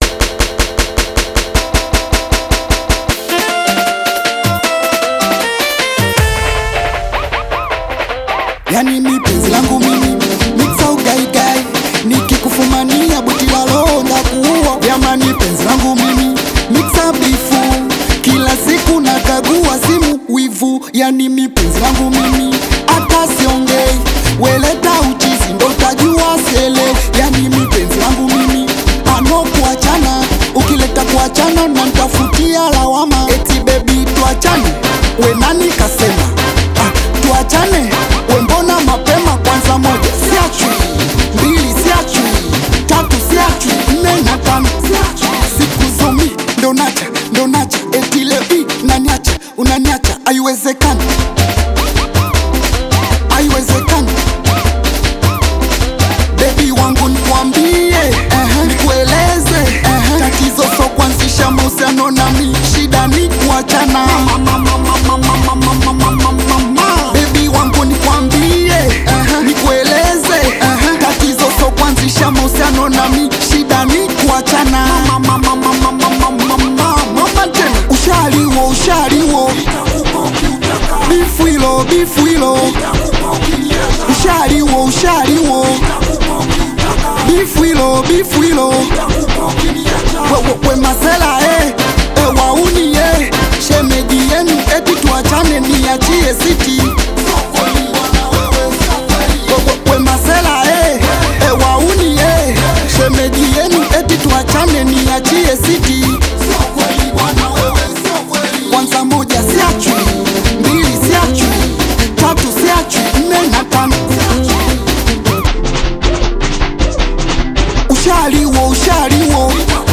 AudioSingeli